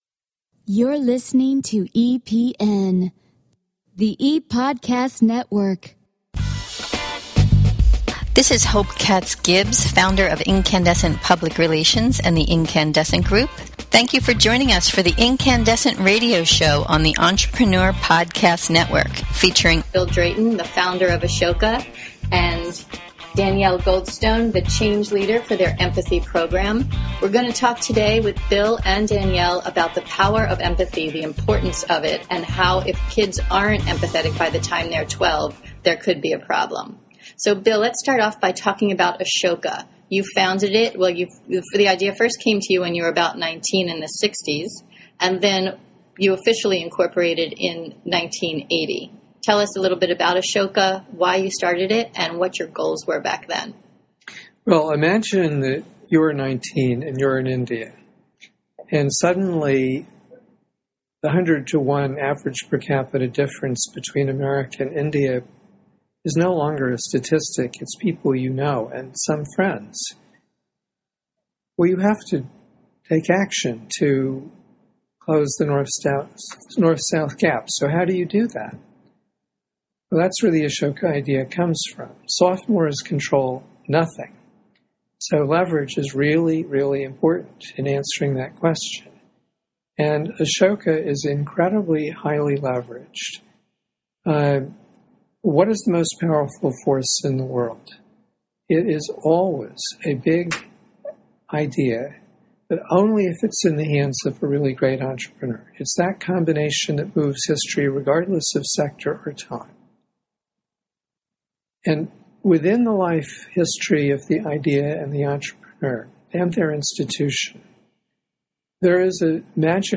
They discuss the power of empathy, and the importance of having empathetic qualities by the time you are 12. • Download our podcast interview with Bill Drayton, right.